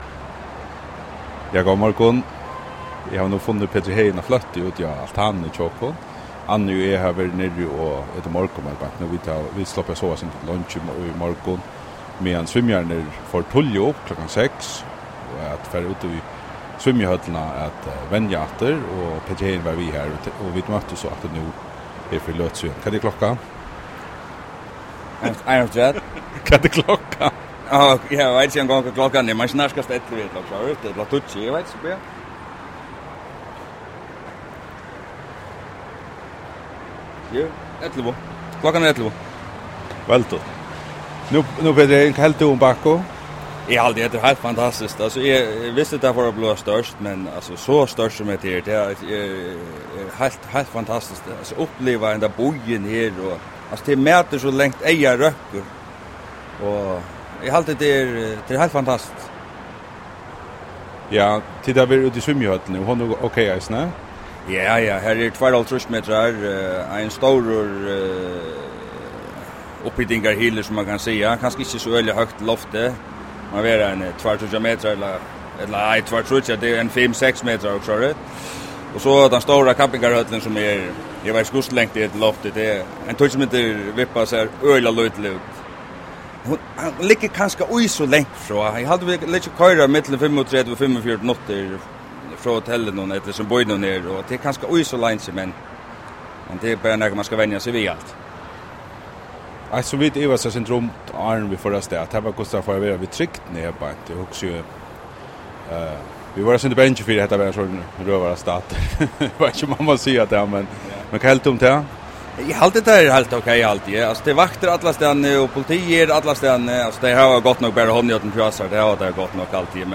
Prát
á altanini í íbúðini hjá føroyska svimjilandsliðnum á Evropeisku Leikum í Baku